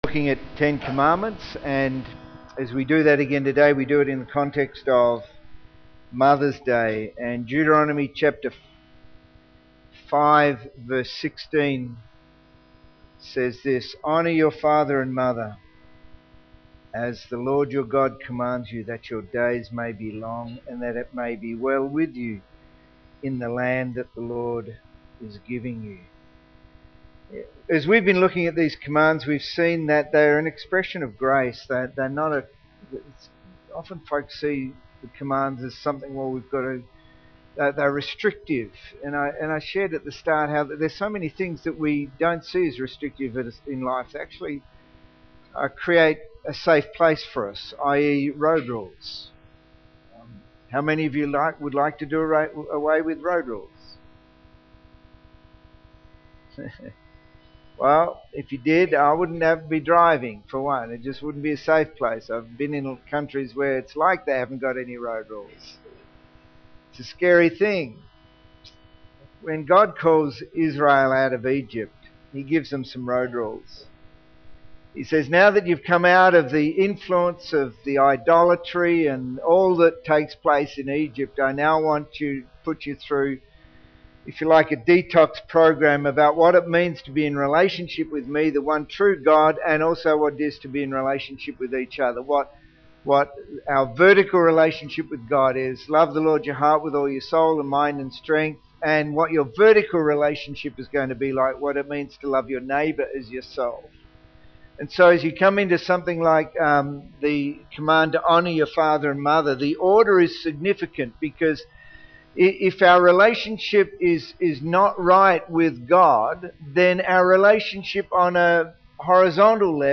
This is a Mothers’ Day sermon, and one thing is for sure; some of you want to put your parents on a pedestal while others want to leave t